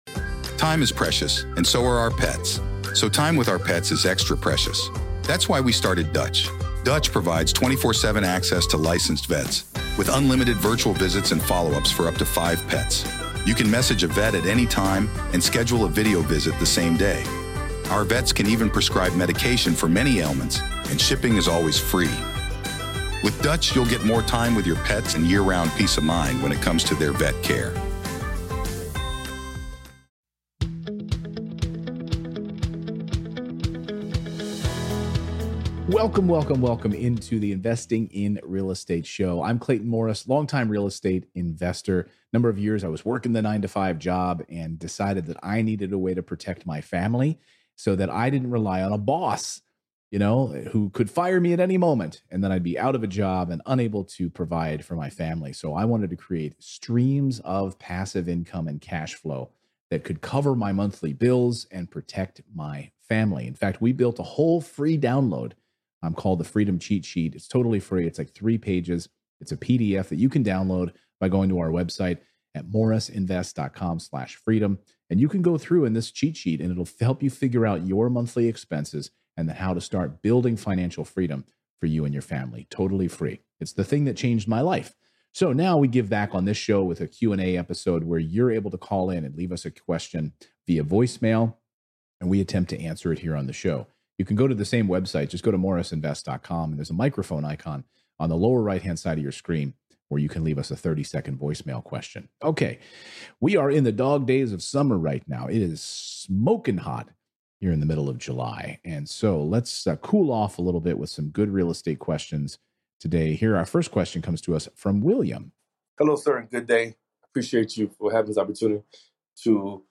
Today's first caller asked a really interesting question. Is it a good idea to use invest in real estate overseas, especially if you plan on moving overseas?